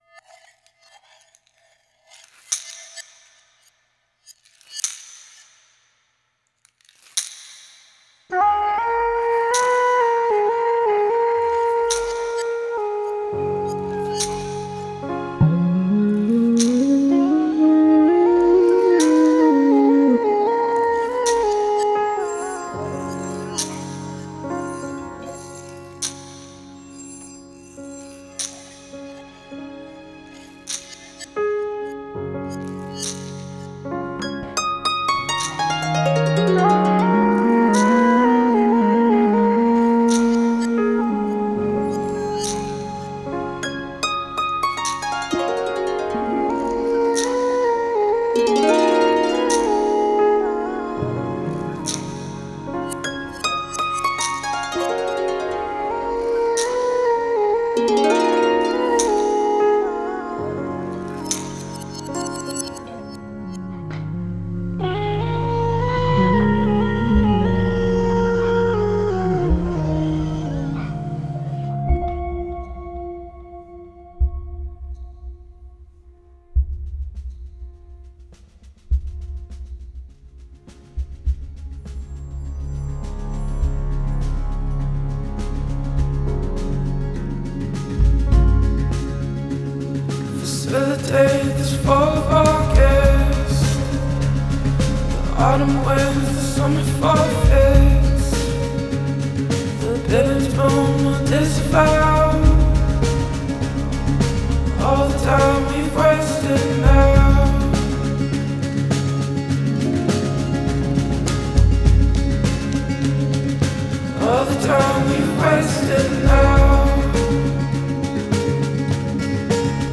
ethereal voice